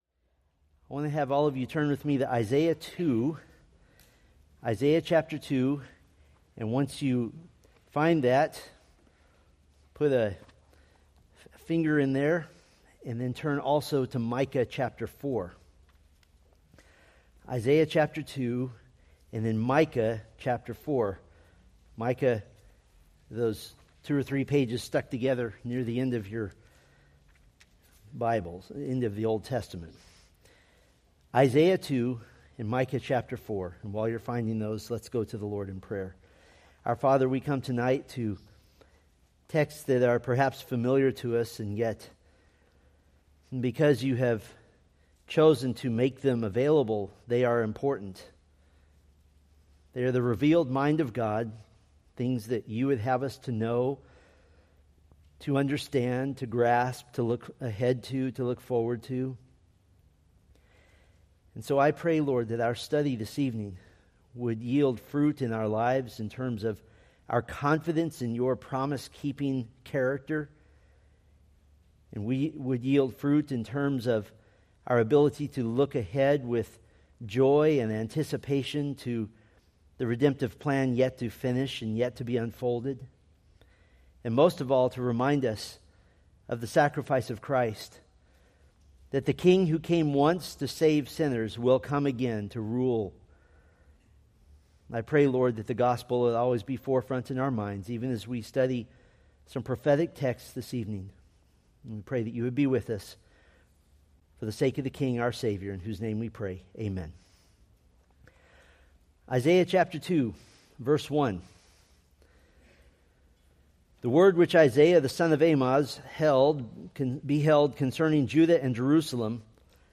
From the Millennium: Old Testament Witnesses sermon series.
Sermon Details